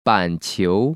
板球[bǎnqiú]